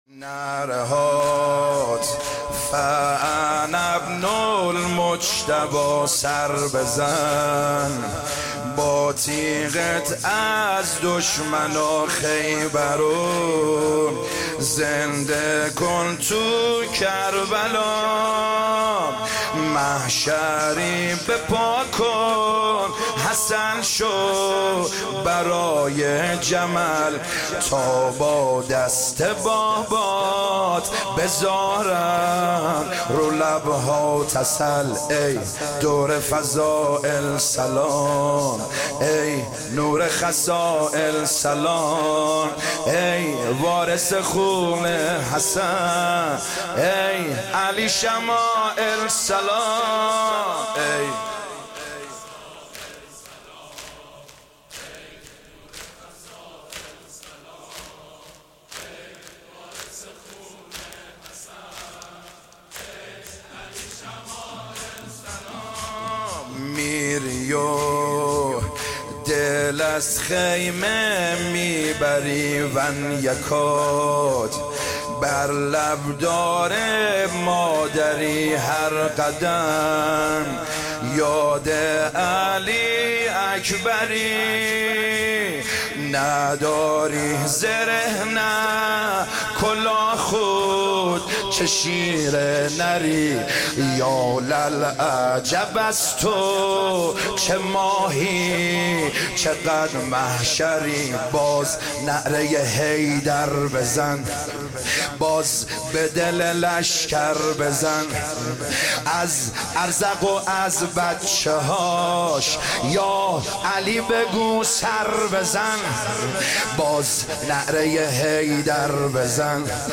شب ششم محرم 1399هیئت کربلا - رفسنجان